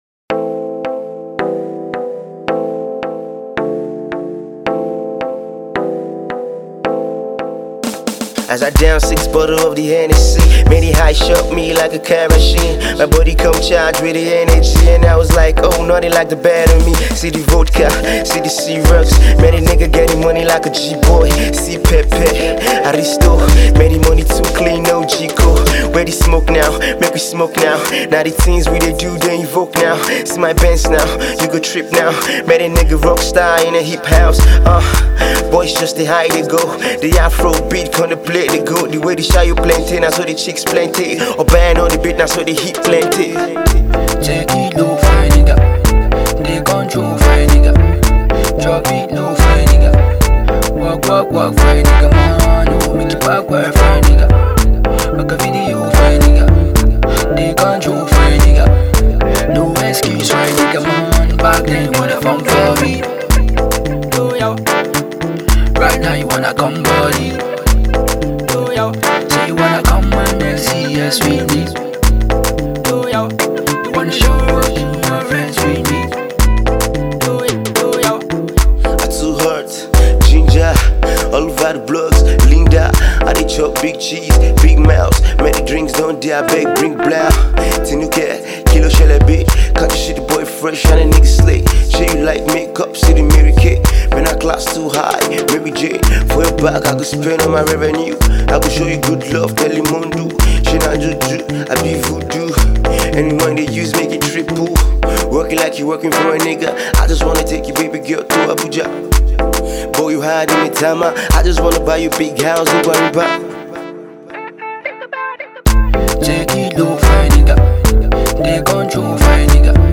a classical vibe